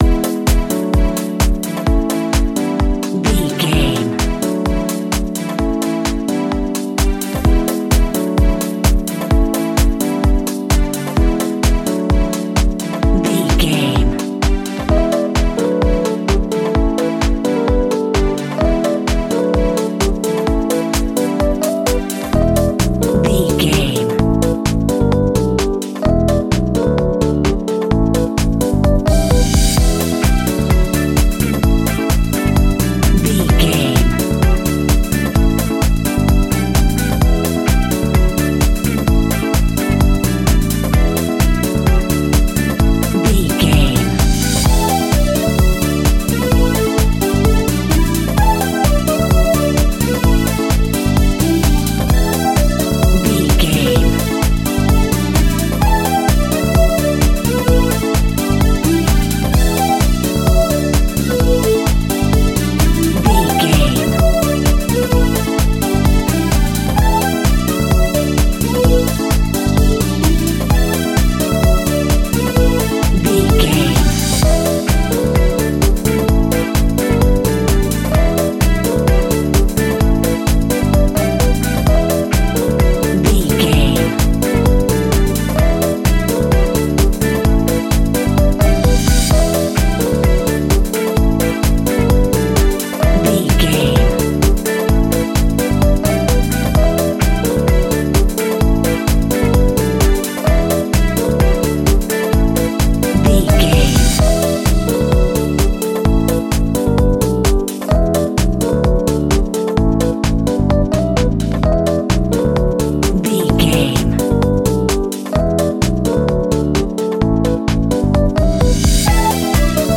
Aeolian/Minor
groovy
smooth
drum machine
synthesiser
electric piano
bass guitar
deep house
nu disco
upbeat